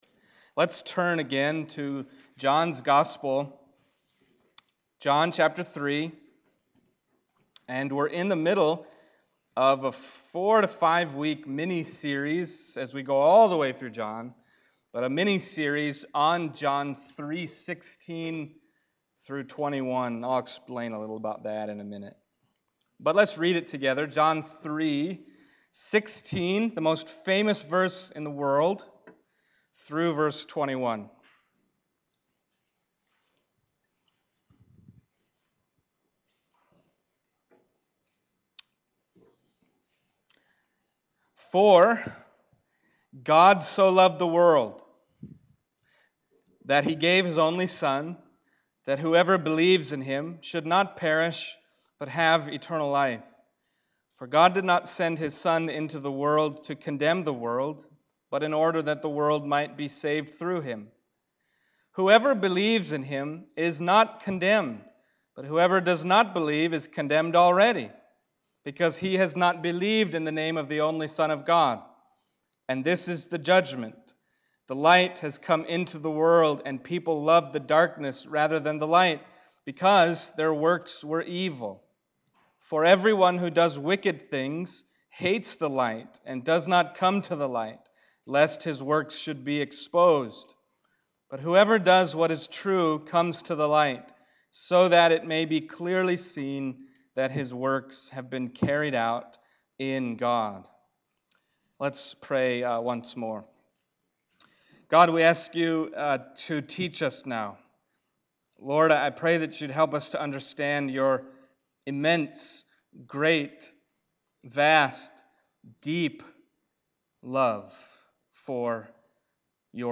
John 3:16 Service Type: Sunday Morning John 3:16 « For God So Loved the World…